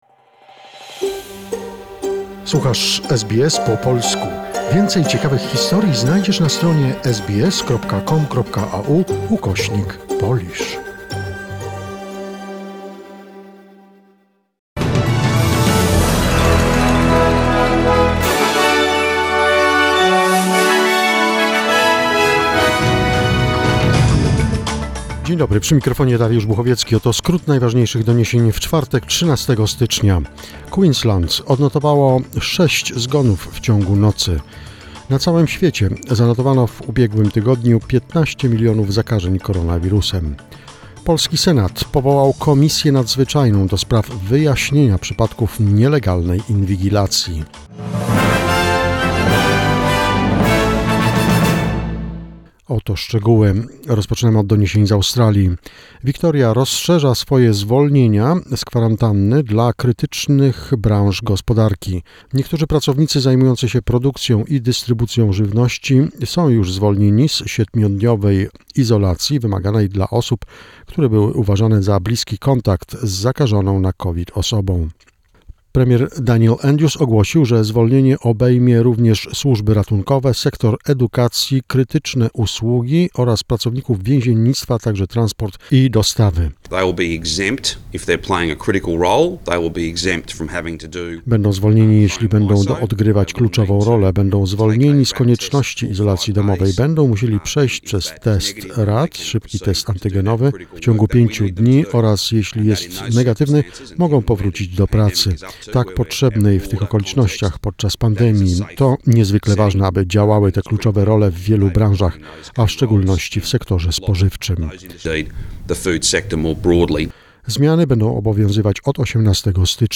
SBS News Flash in Polish, 13 January 2022